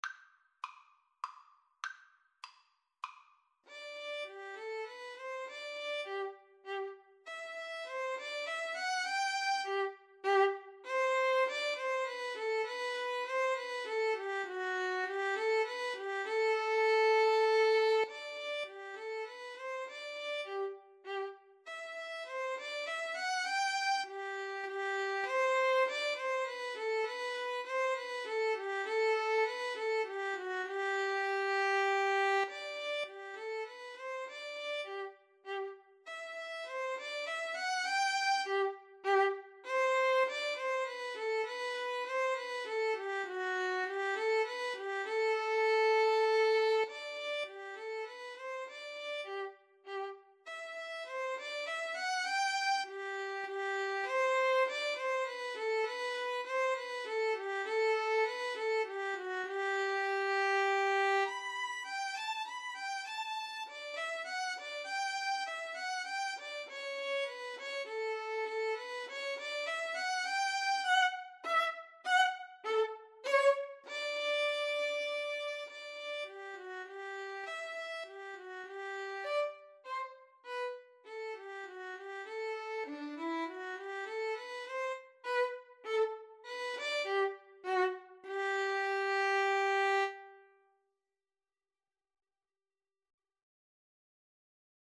3/4 (View more 3/4 Music)
Classical (View more Classical Violin-Viola Duet Music)